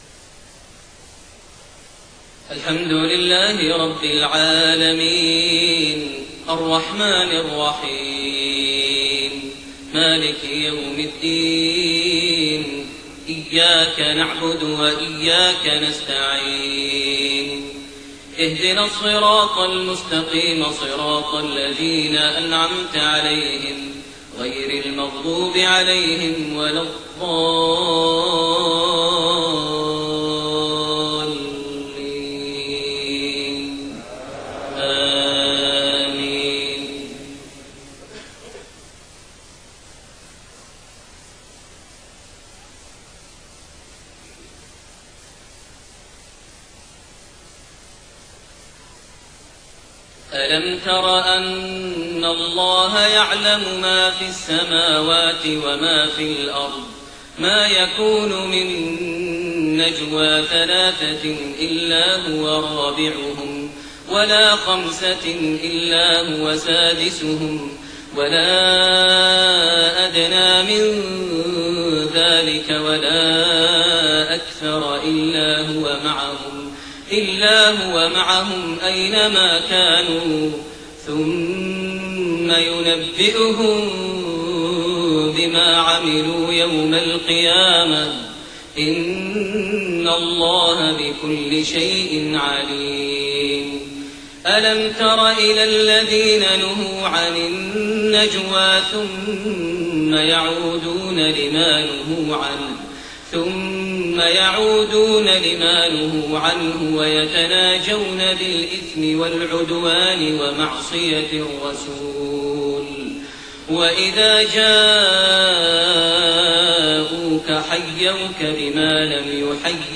Maghrib prayer from Surat Al-Mujaadila > 1430 H > Prayers - Maher Almuaiqly Recitations